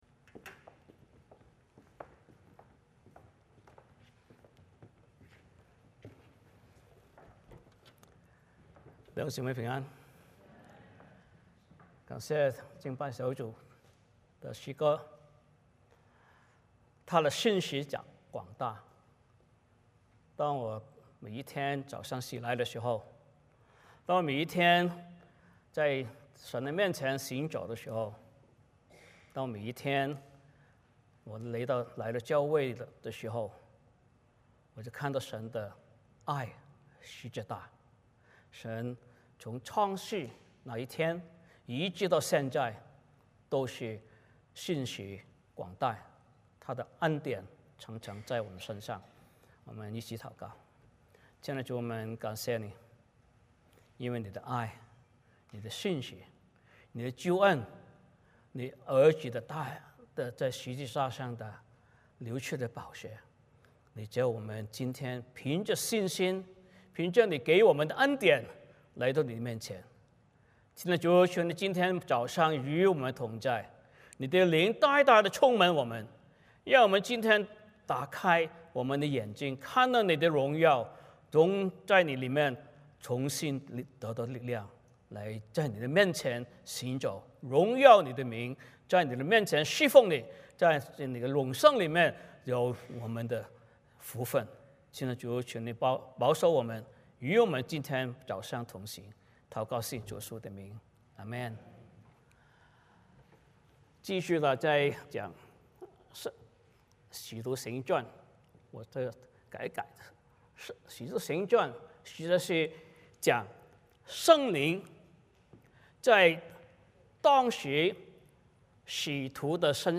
使徒行传 3:1-20 Service Type: 主日崇拜 欢迎大家加入我们的敬拜。